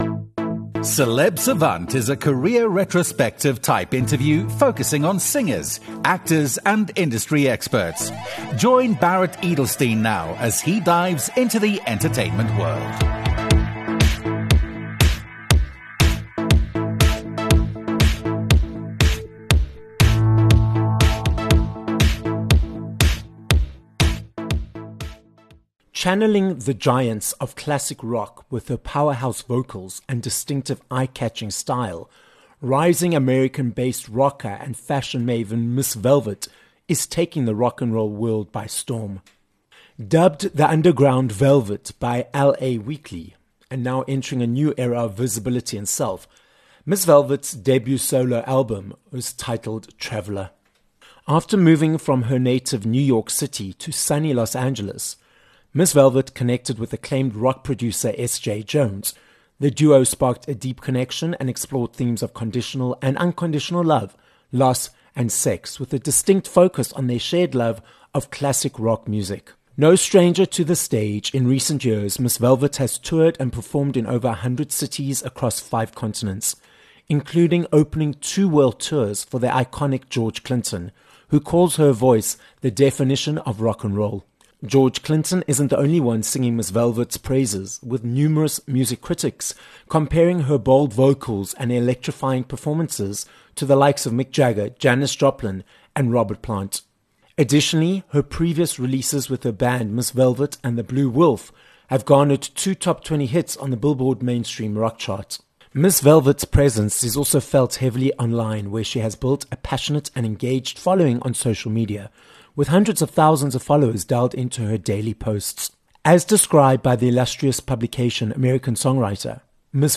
rock artist